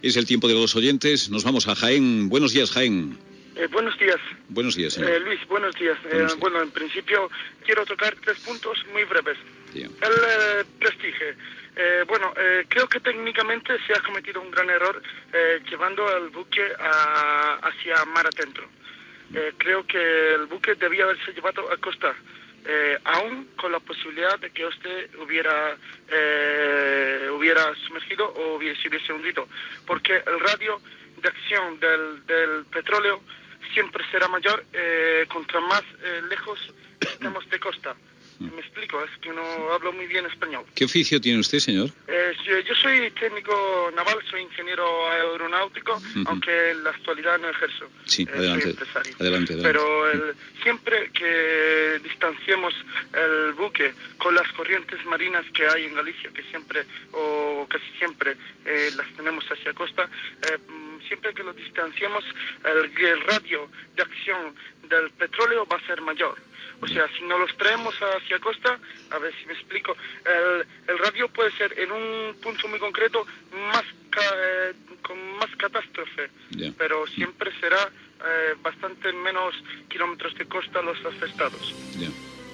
Intervenció d'un oient sobre el vaixell "Prestige" i el vessament de petroli a Galicia
Info-entreteniment